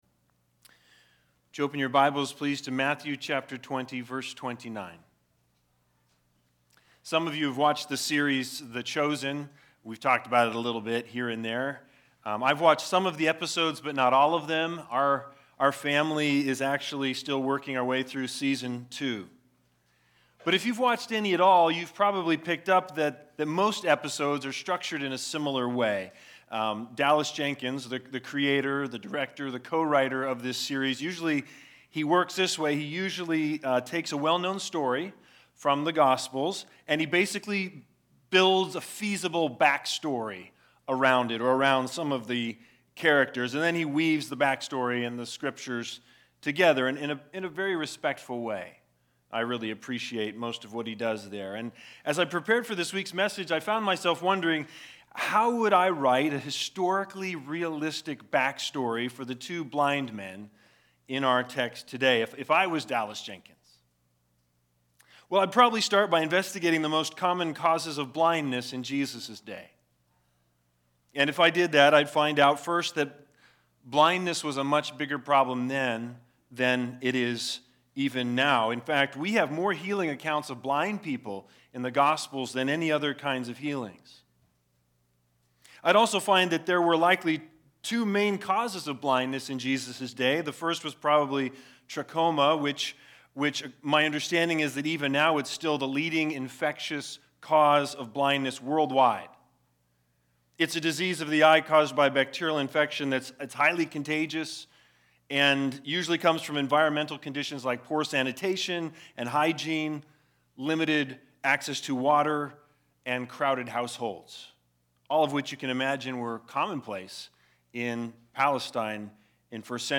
Matthew 20:29-34 Service Type: Sunday Sermons Big Idea